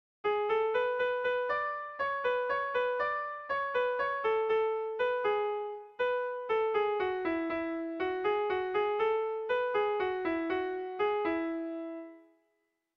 Dantzakoa
Albokaz jotzeko da doinuaren azken zatia.
AB